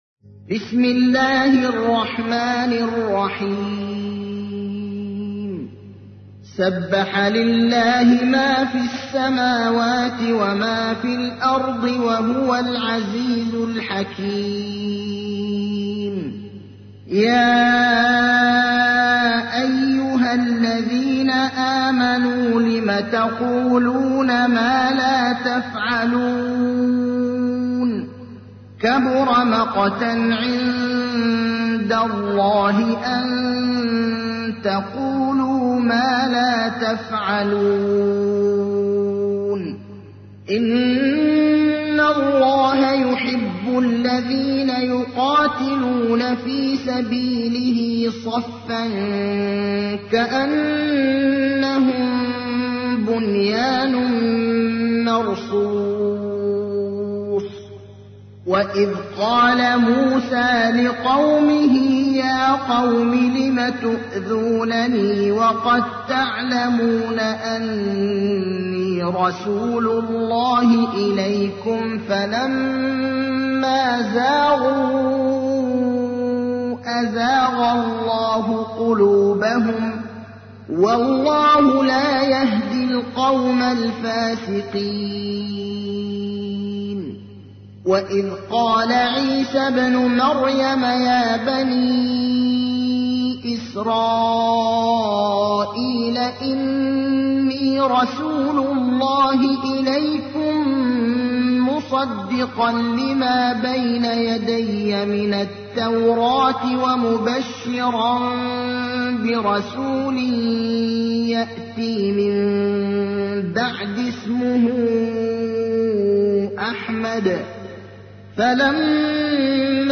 تحميل : 61. سورة الصف / القارئ ابراهيم الأخضر / القرآن الكريم / موقع يا حسين